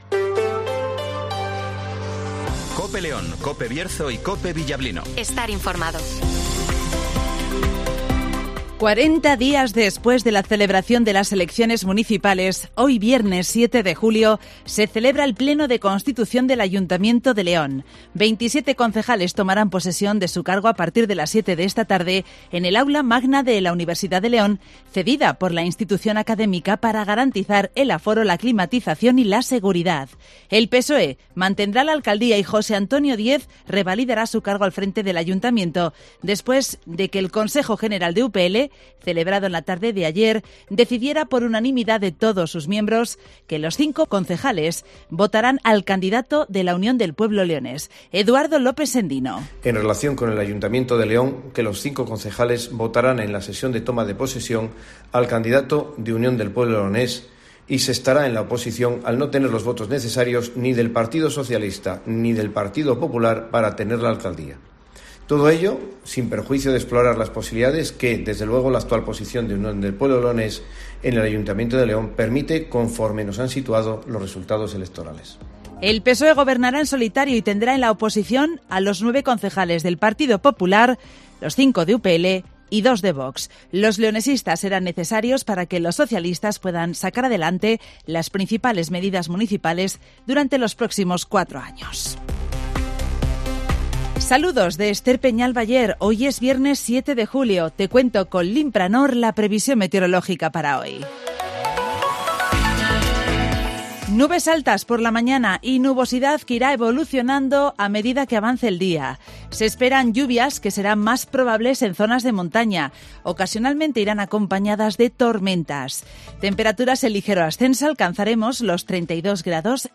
- Informativo Matinal 08:25 h